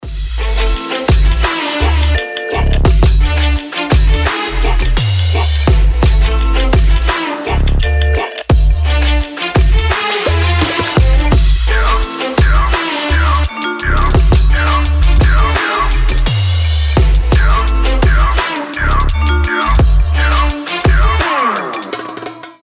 басы
восточные
арабские
Арабский трэп